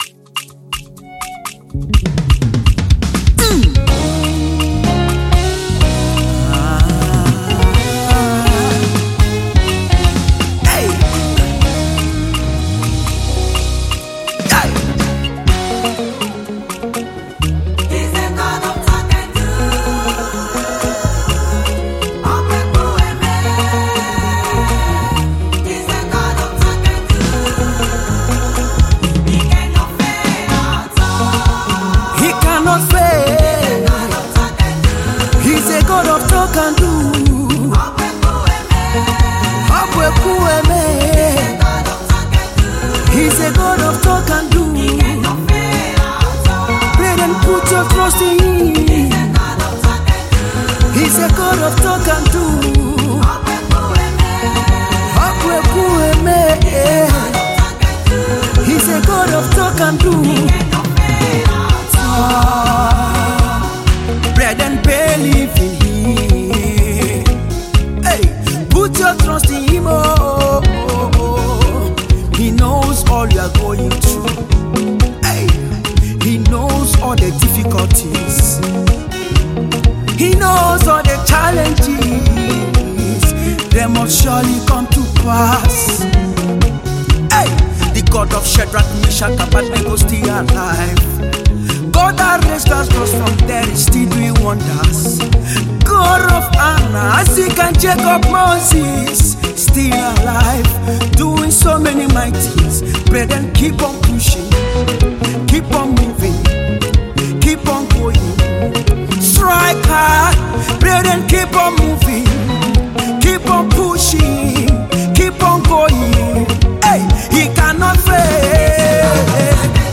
Fast rising gospel music minister